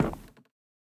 Minecraft Version Minecraft Version latest Latest Release | Latest Snapshot latest / assets / minecraft / sounds / block / chiseled_bookshelf / step4.ogg Compare With Compare With Latest Release | Latest Snapshot
step4.ogg